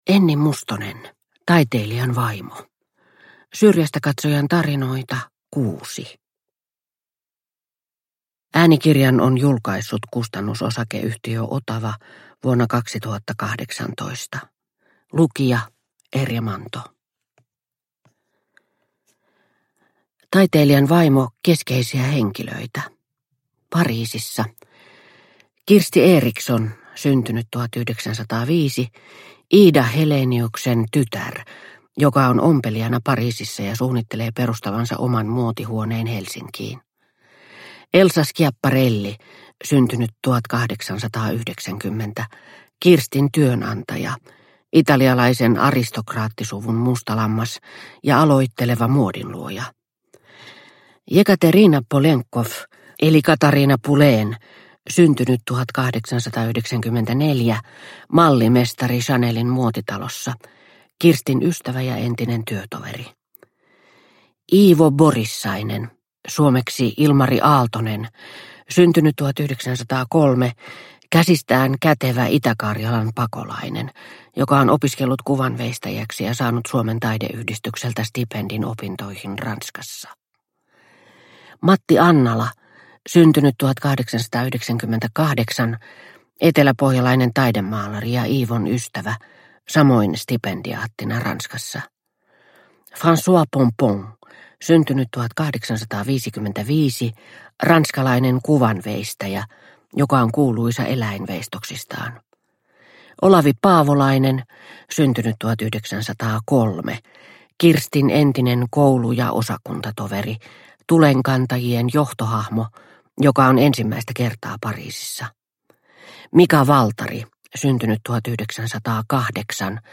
Taiteilijan vaimo – Ljudbok – Laddas ner